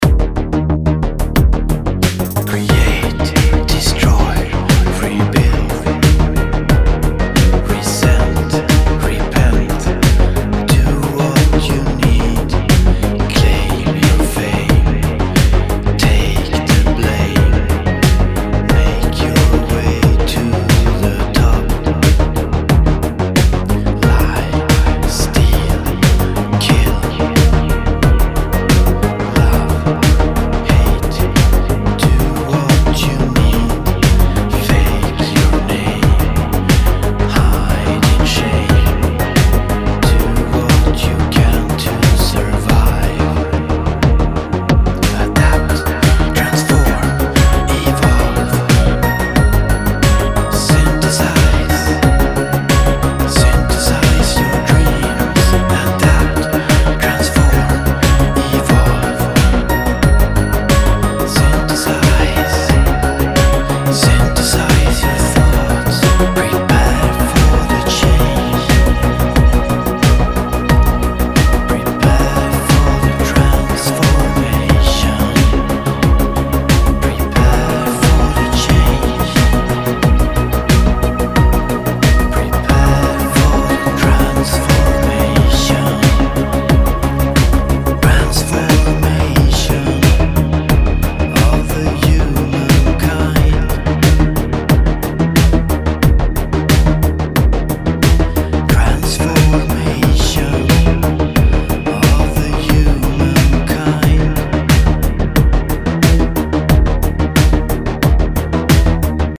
Here´s a short vocaldemo. Just one of the takes, still working on fixing up the other ones. Levels are not adjusted yet.
> I love it ! with your voices whispering it sounds very complete:
> I like very much your chorus ... melancholic and dreamy :)
dmg_transformation_vocaldemo.mp3